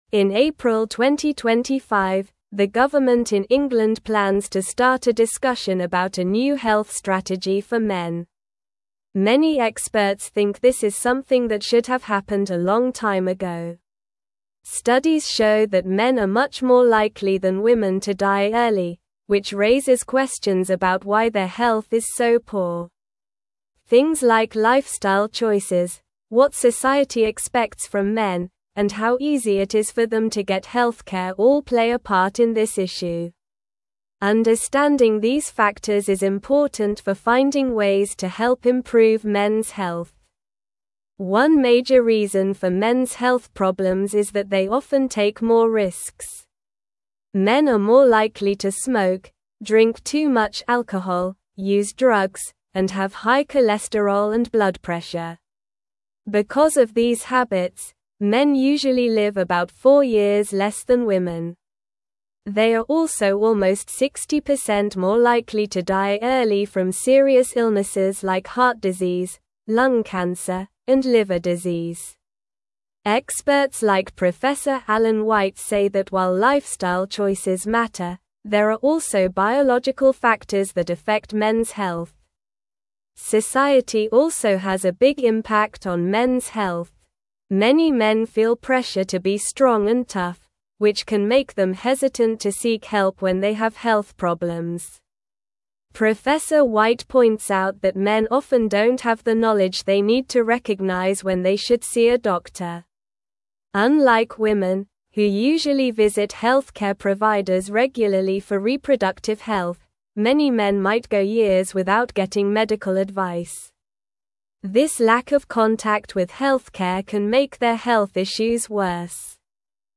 Slow
English-Newsroom-Upper-Intermediate-SLOW-Reading-UK-Government-Launches-Consultation-for-Mens-Health-Strategy.mp3